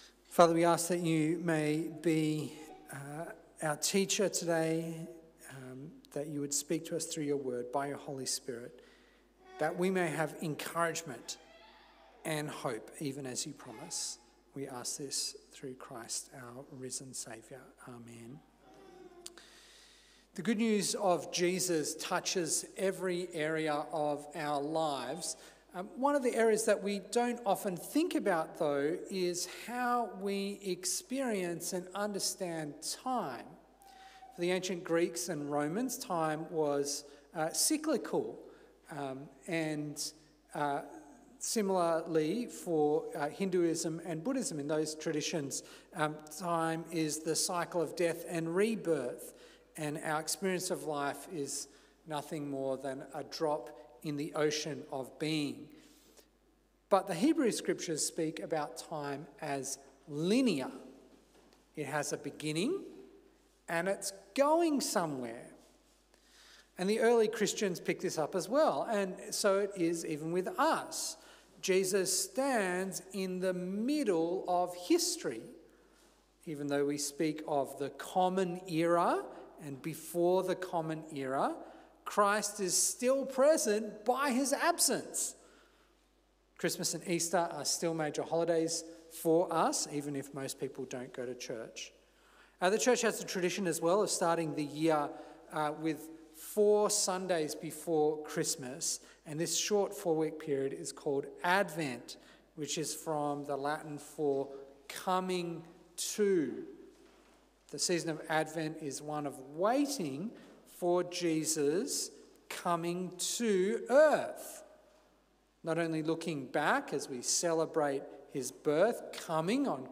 An advent sermon